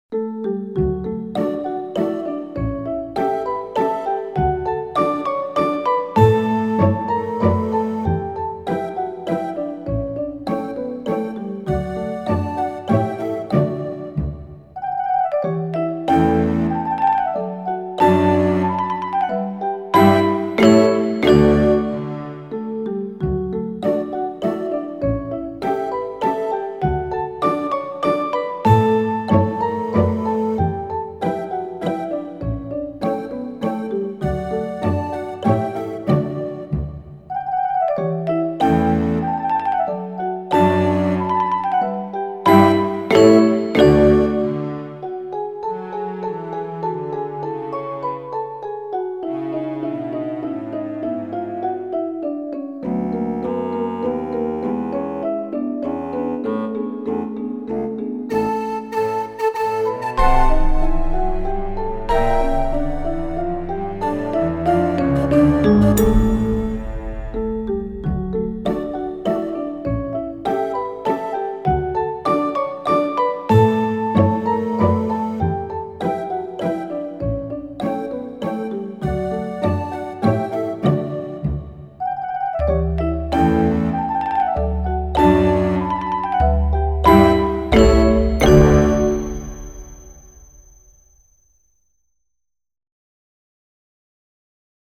Классическая музыка величайшего композитора для взрослых и детей.